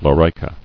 [lo·ri·ca]